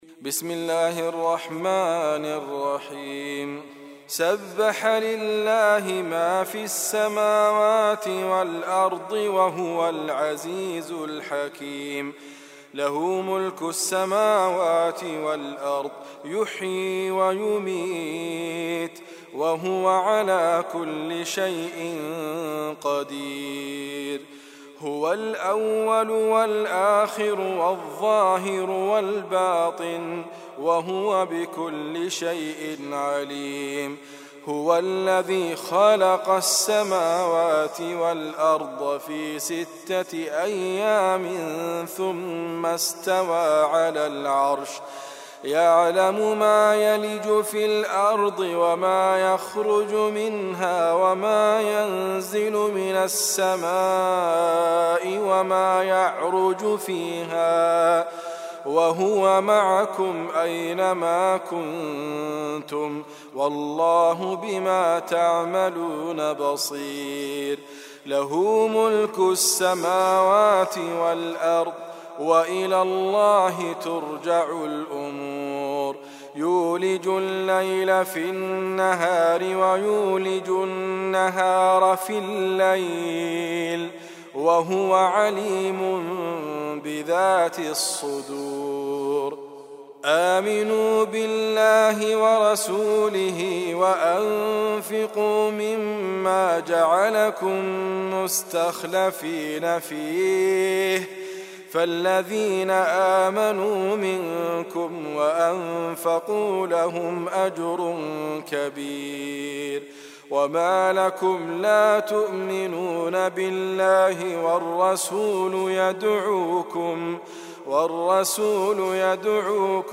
الحديد ,ادريس ابكر ,القران الكريم